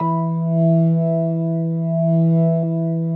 B3LESLIE E 4.wav